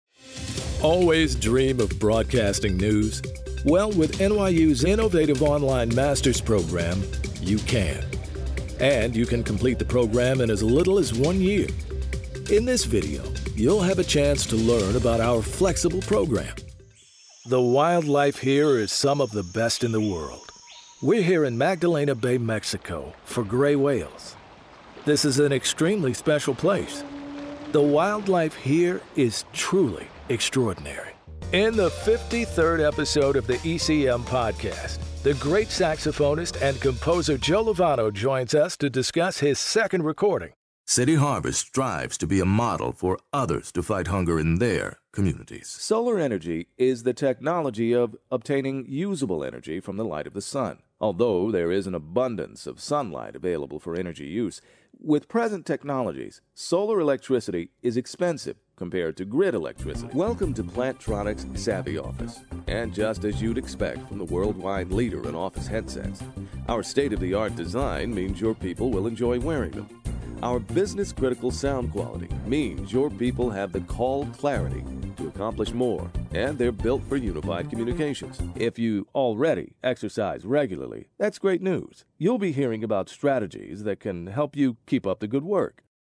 A Broadcast Professional with Decades of Commercial, Corporate, and Documentary Narration
Studio & Services: Professional home studio providing broadcast-quality audio with fast turnaround.
Corporate Narration & E-Learning Demo